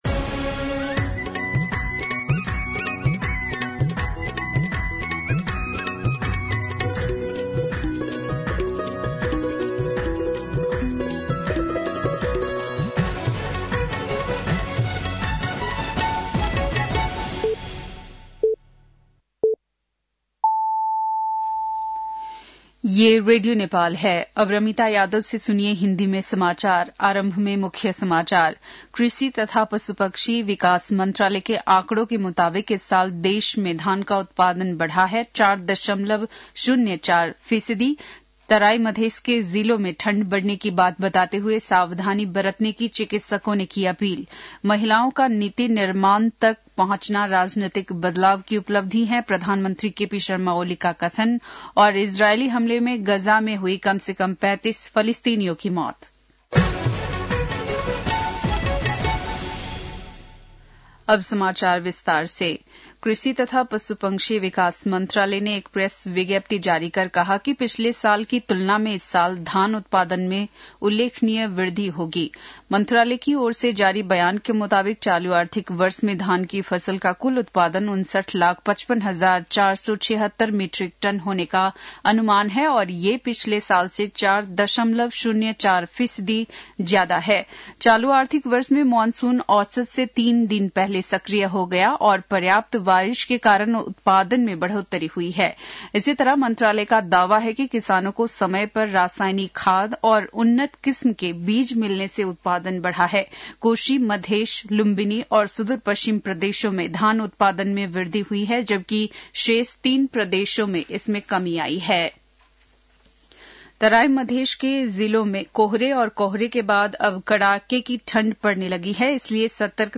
बेलुकी १० बजेको हिन्दी समाचार : २० पुष , २०८१
10-PM-Hindi-News-9-19.mp3